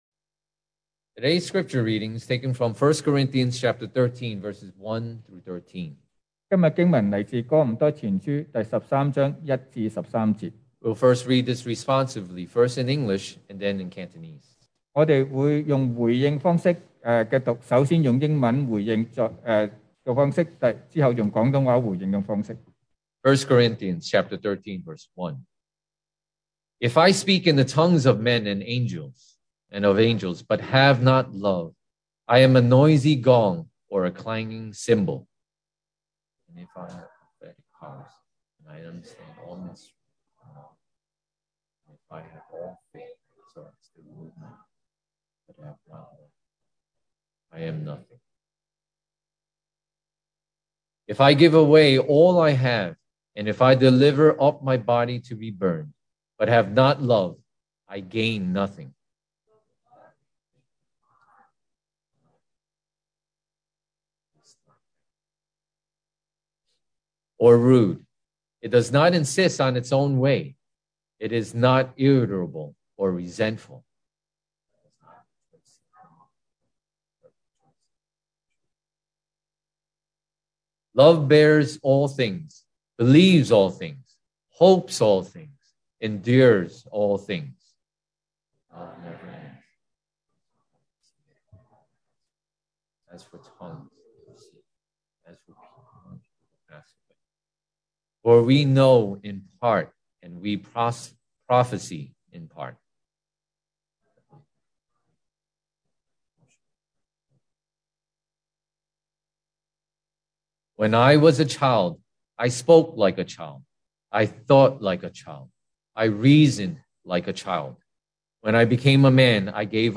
Series: 2022 sermon audios
Service Type: Sunday Morning